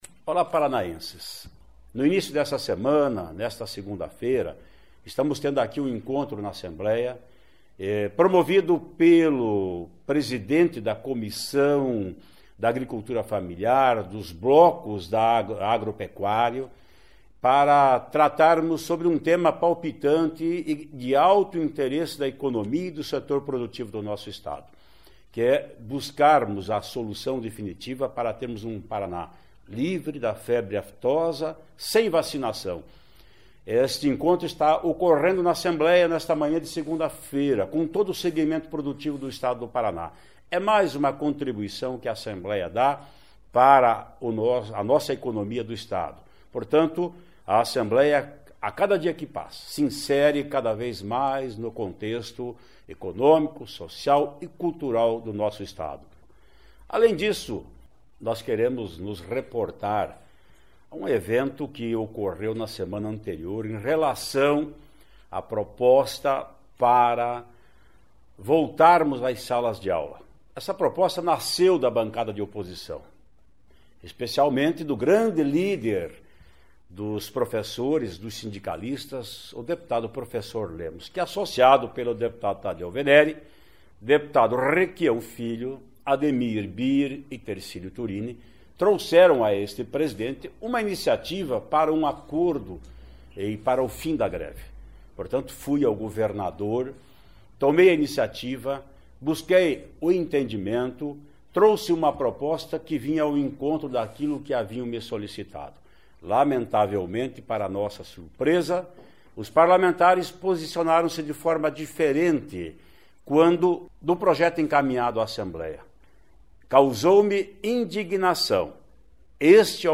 Presidente da Assembleia Legislativa, Ademar Traiano, fala sobre o que está acontecendo no Legislativo e Faz apelo aos professores